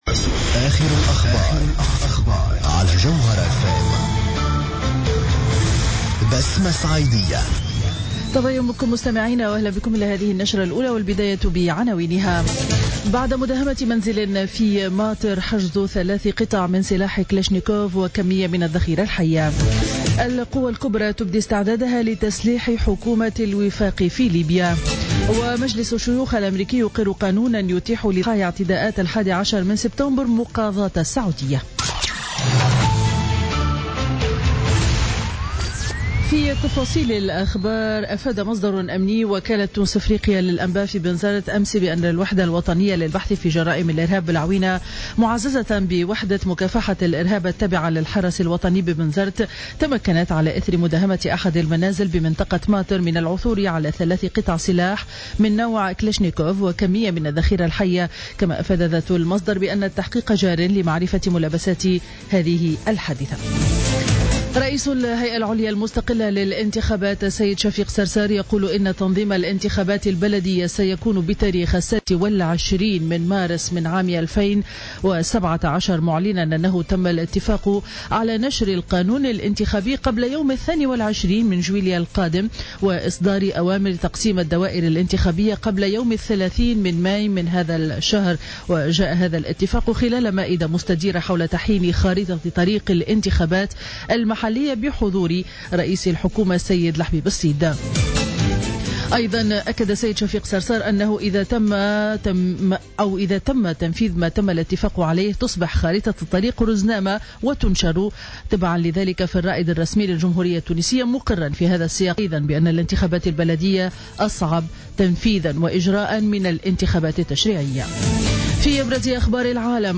نشرة أخبار السابعة صباحا ليوم الأربعاء 18 ماي 2016